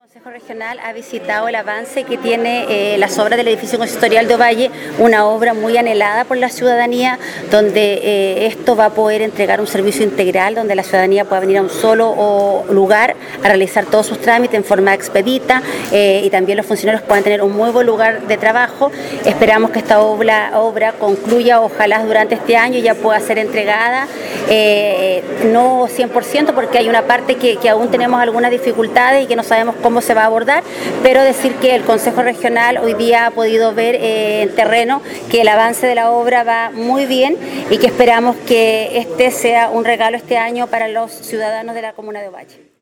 “El edificio Municipal de Ovalle, es una obra muy anhelada por la ciudadanía, que va a entregar un servicio integral, y donde la ciudadanía podrá venir a un solo lugar a realizar todos los trámites en forma expedita, y los funcionarios tendrán un muy buen lugar de trabajo, señaló la consejera Paola Cortes, presidenta de la comisión de Régimen Interno.